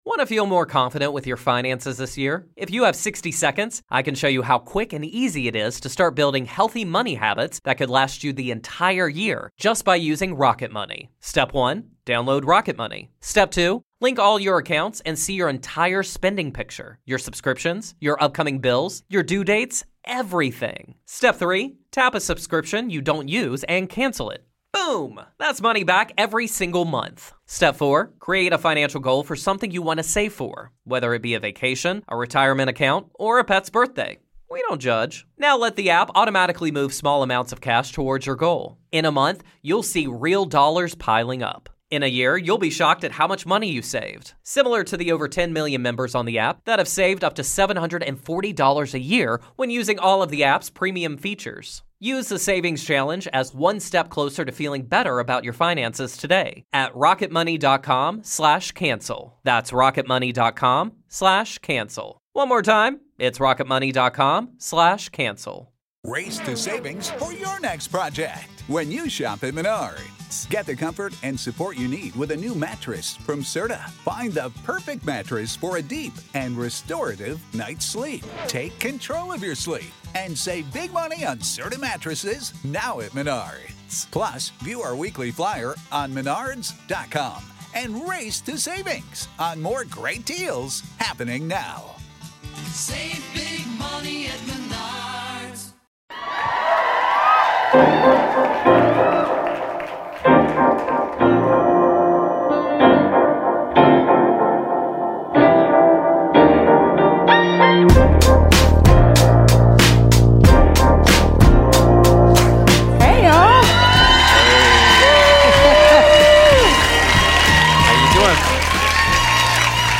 Unforced Errors (LIVE from Philadelphia, PA)